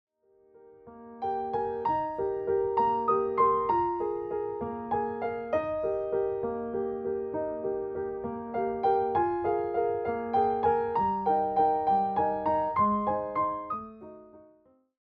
” where bright, dancing passages evoke childhood excitement.